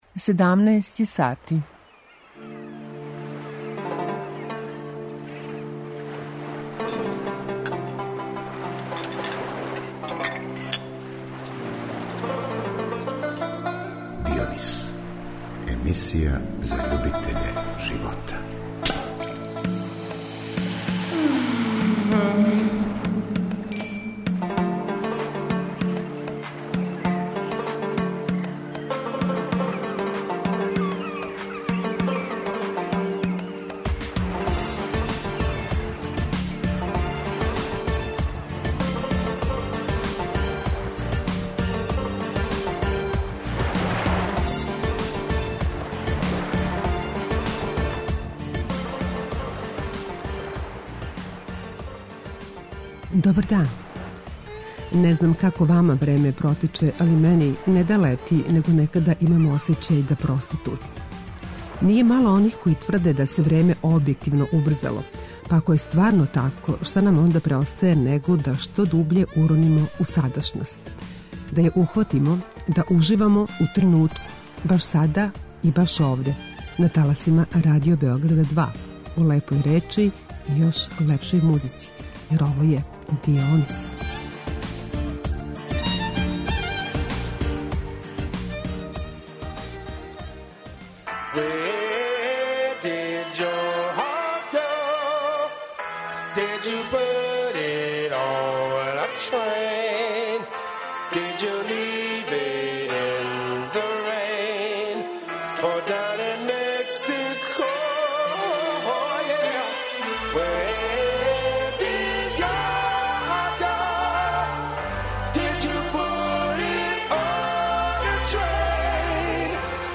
И ове недеље својим садржајем емисија Дионис позива слушаоце да уживаjу у лепој речи и још лепшој музици.